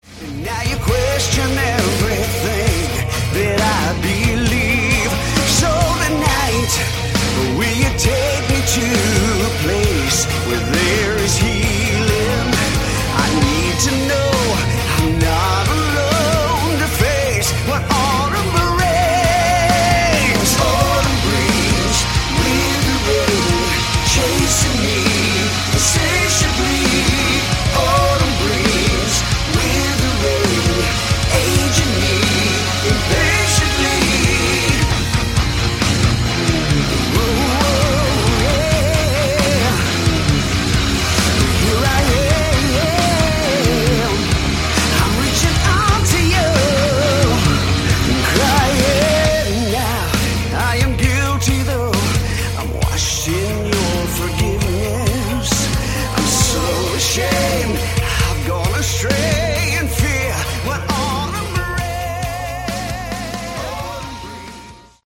Category: Hard Rock
lead vocals, keyboards
guitars, vocals
drums
bass